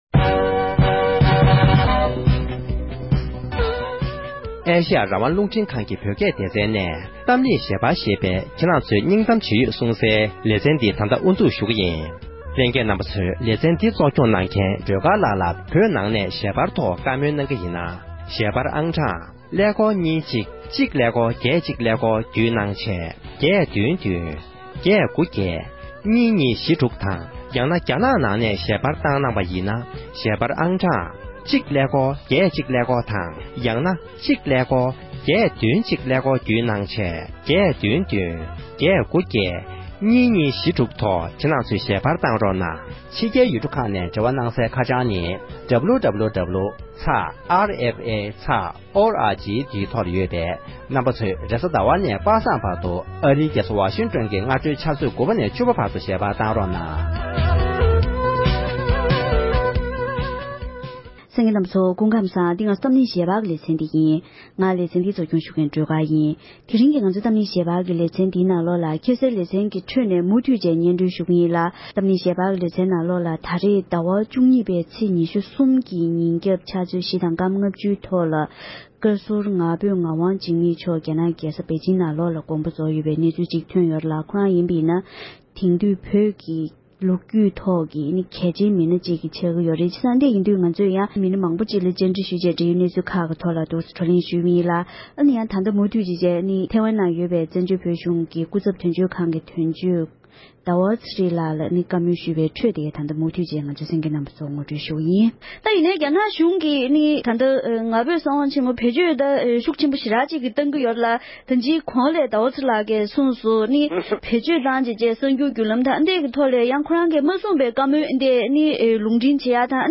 ༄༅༎དེ་རིང་གི་གཏམ་གླེང་ཞལ་པར་གྱི་ལེ་ཚན་ནང་དུ་ཕྱི་ཟླ་བཅུ་གཉིས་པའི་ཚེས་ཉེར་གསུམ་ཉིན་བཀའ་ཟུར་ང་ཕོད་ངག་དབང་འཇིགས་མེད་མཆོག་དགོངས་པ་རྫོགས་པའི་གནས་ཚུལ་ཐོག་ནས་འབྲེལ་ཡོད་མི་སྣ་དང་ལྷན་དུ་བགྲོ་གླེང་ཞུས་པའི་དུམ་མཚམས་གཉིས་པར་གསན་རོགས༎